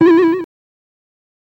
Video Game Boing On Head, You're Dead